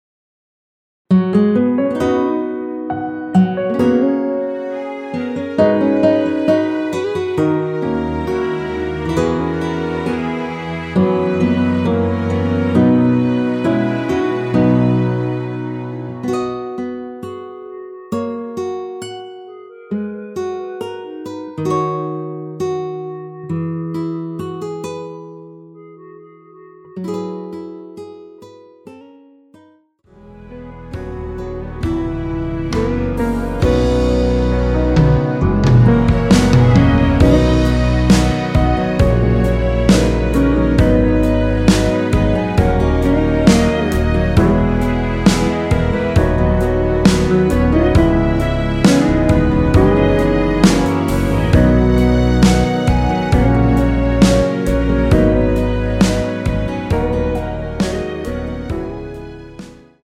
원키에서(-6)내린 멜로디 포함된 MR입니다.(미리듣기 확인)
앞부분30초, 뒷부분30초씩 편집해서 올려 드리고 있습니다.
중간에 음이 끈어지고 다시 나오는 이유는